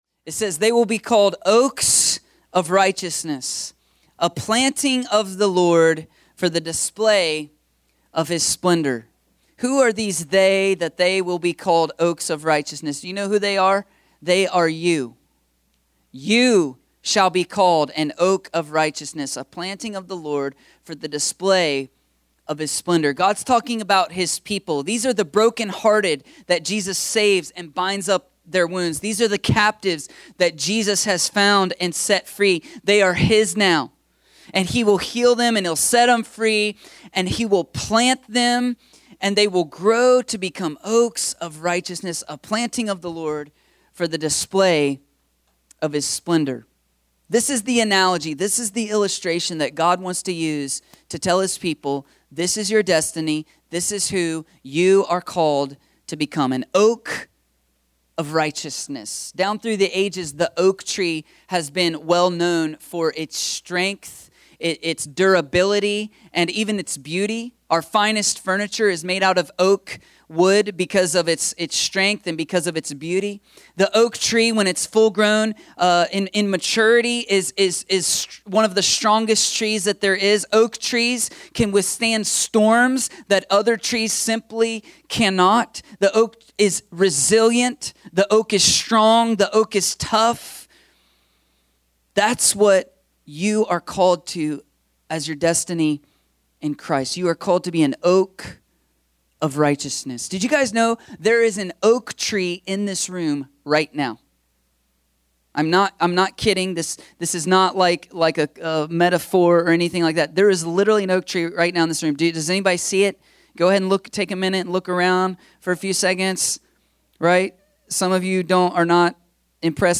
A sermon from the series “Growth Spurt.”…